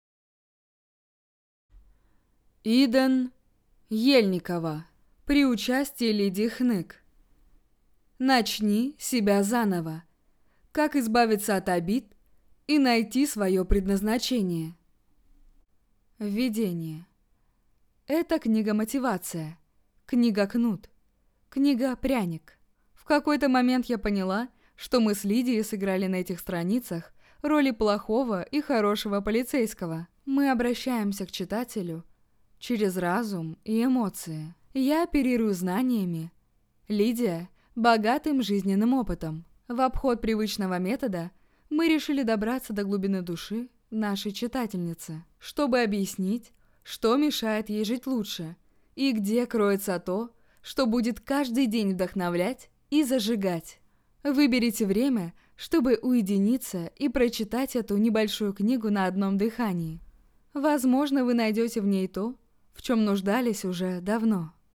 Аудиокнига Начни себя заново. Как избавиться от обид и найти своё предназначение | Библиотека аудиокниг